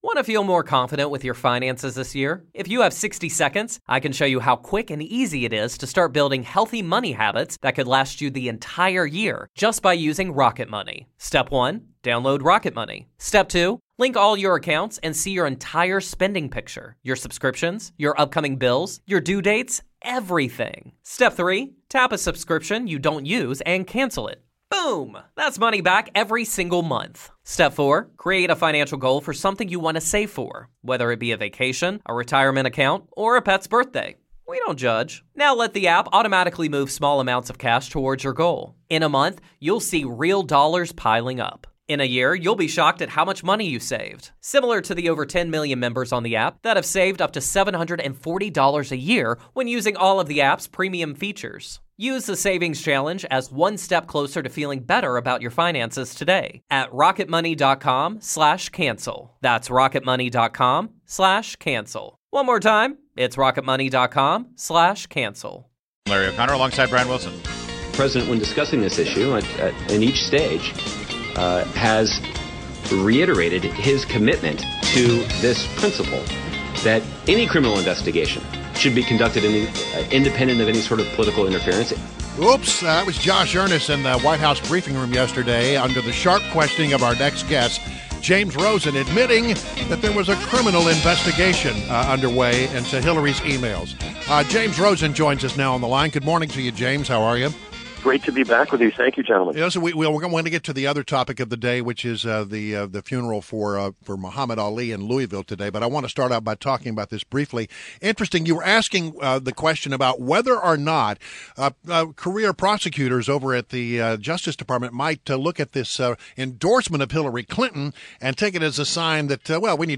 WMAL Interview - JAMES ROSEN - 06.10.16